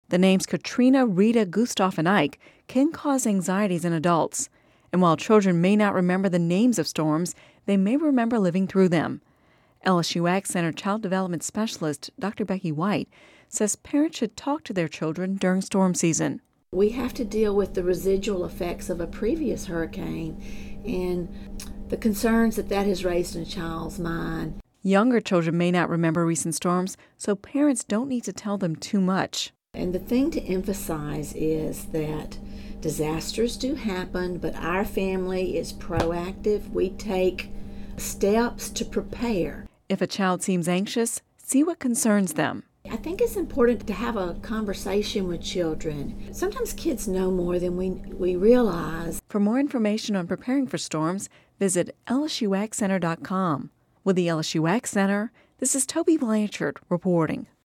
(Radio News 09/06/10) The names Katrina, Rita, Gustav and Ike can cause anxieties in adults, and while children may not remember the names of storms, they may remember living through them.